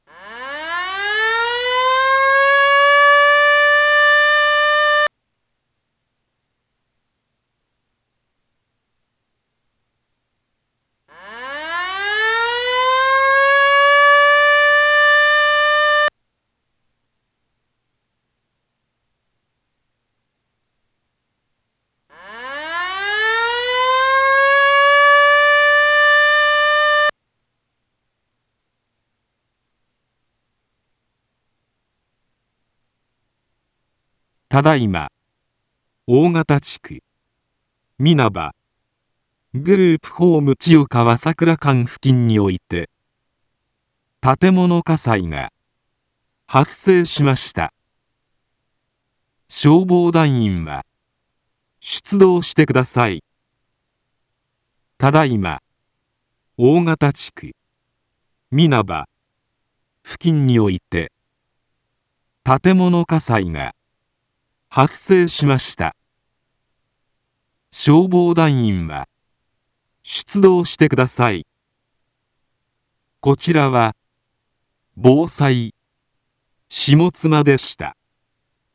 一般放送情報
Back Home 一般放送情報 音声放送 再生 一般放送情報 登録日時：2024-03-18 11:11:24 タイトル：火災報 インフォメーション：ただいま、大形地区、皆葉、ぐるーぷほーむちよかわさくらかん 付近において、 建物火災が、発生しました。